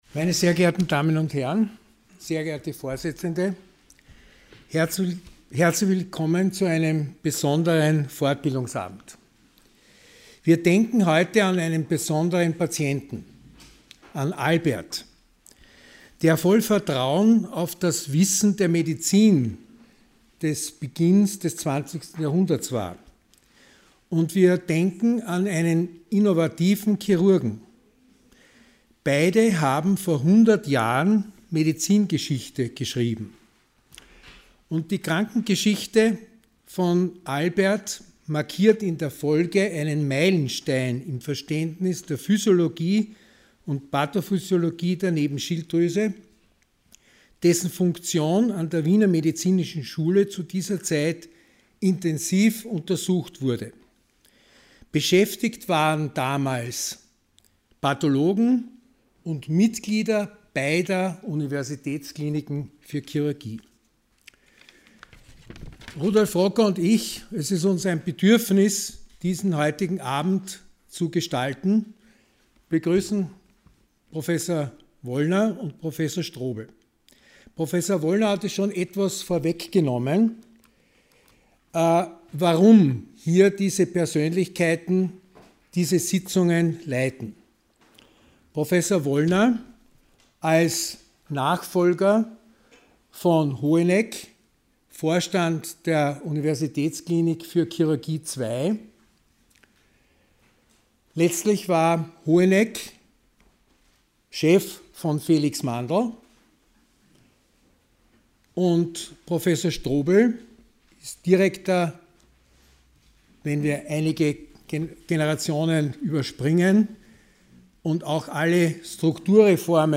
Sie haben den Vortrag noch nicht angesehen oder den Test negativ beendet.